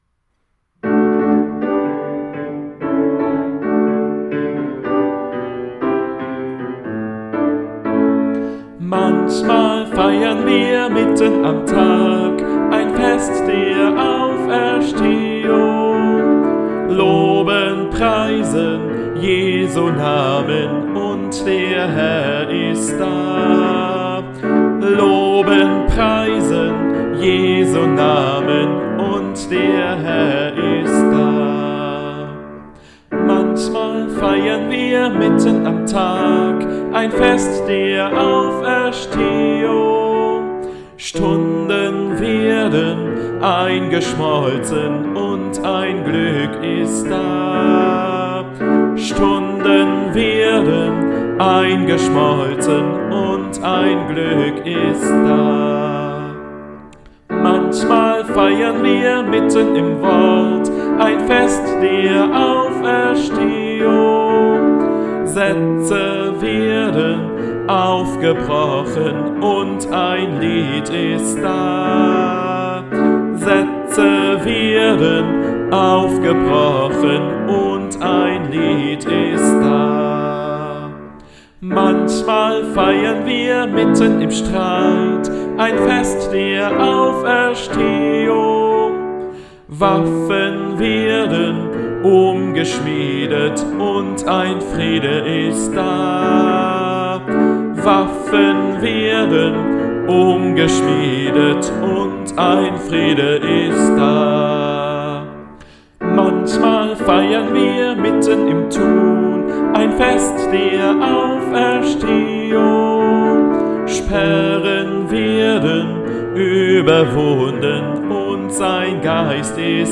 Gottesdienst vom Ostersonntag nachhören
(4 MB) Halleluja, Evangelium & Glaubensbekenntnis (4 MB) Predigt (5 MB) Vortragslied: Manchmal feiern wir mitten am Tag (3 MB) Ameise Astrid ist fündig geworden...
00_-_Desktop_-_Manchmal_feiern_wir_Klavier_Gesang.mp3